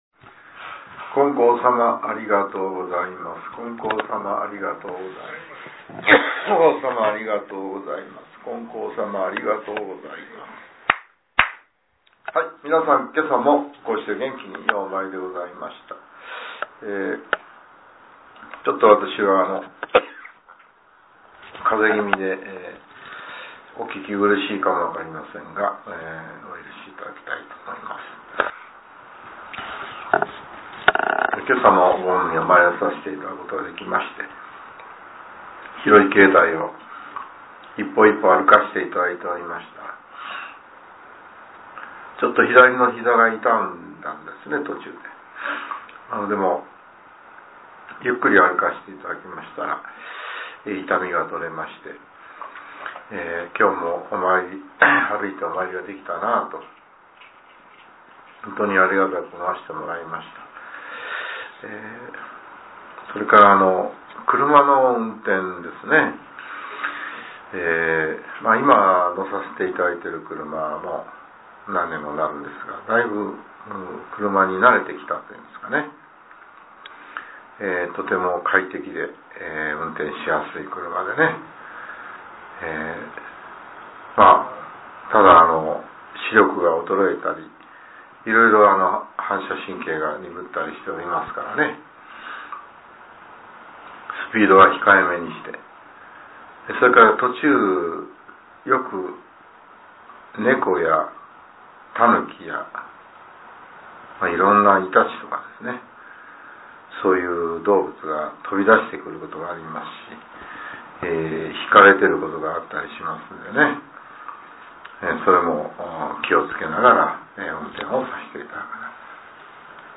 令和６年１２月６日（朝）のお話が、音声ブログとして更新されています。